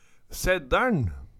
DIALEKTORD PÅ NORMERT NORSK SeddæLn Sedalen Tilleggsopplysningar Kjelde